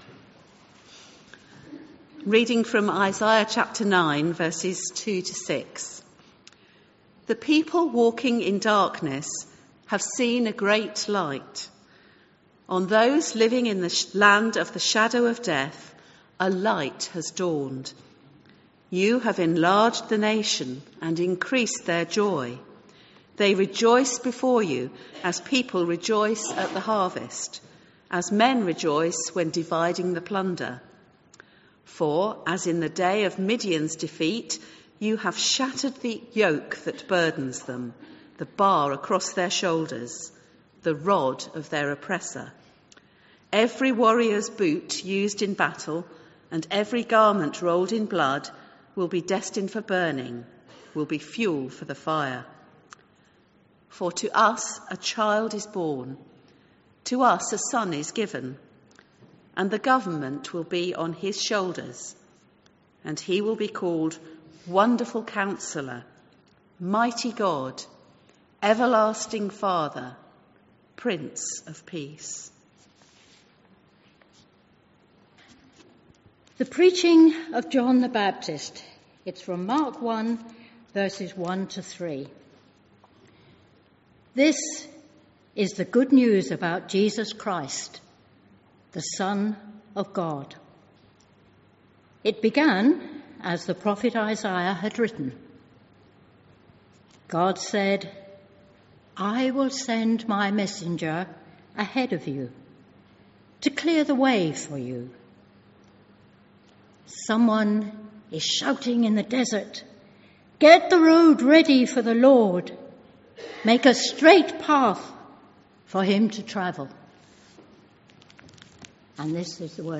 An audio recording of the service is available.